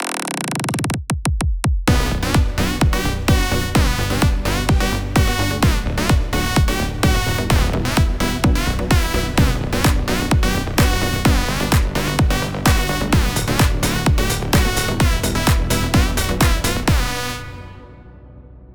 וואלה זה יצא ממש טוב במיוחד ההתחלה לדעתי הקלפים פה לא קשורים וצריך להחליף אותם והסאונדים מתחילת הבילדאפ עד הסוף זה סאונדים של אורגן והם קצת ישנים וגם צריך פה מיקס